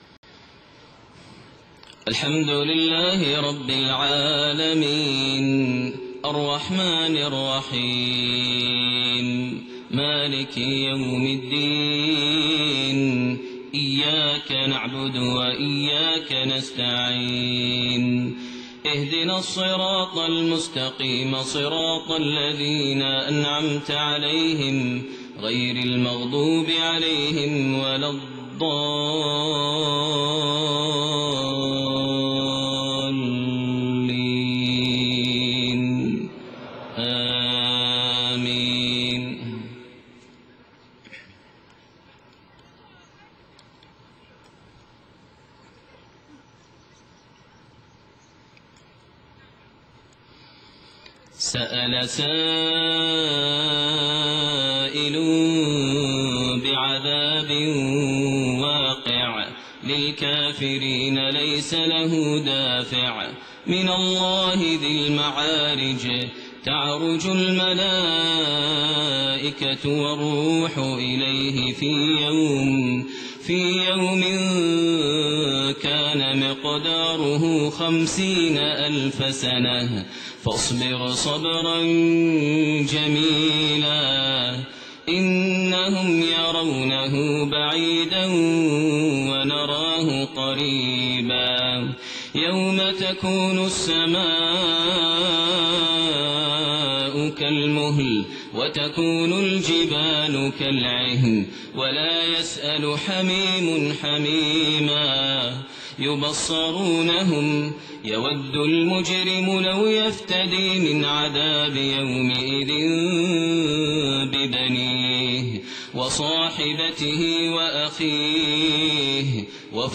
fajr prayer | Surah Al-Ma'aarij > 1429 H > Prayers - Maher Almuaiqly Recitations